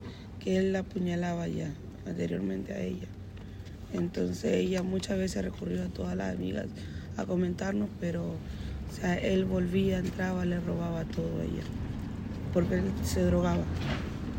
Una amiga de la víctima relató que el hombre ya había apuñalado a la mujer anteriormente.